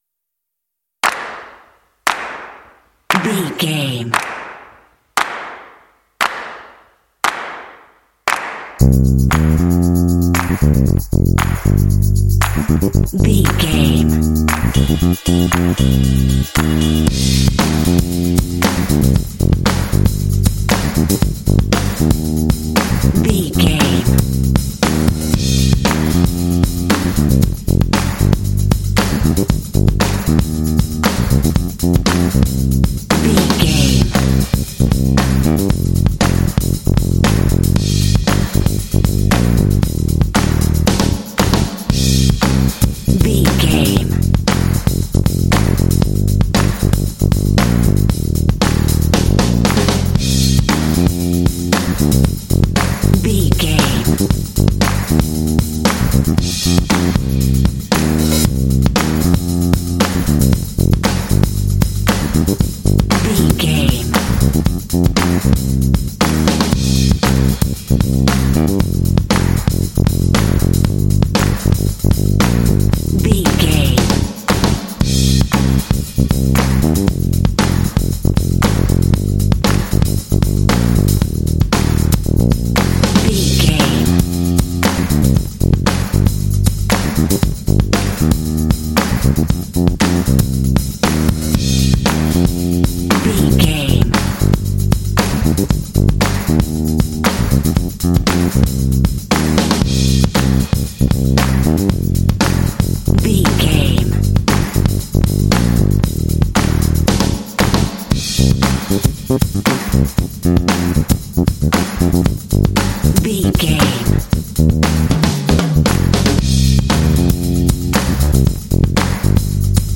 Aeolian/Minor
funky
happy
bouncy
groovy
bass guitar
percussion
drums
Funk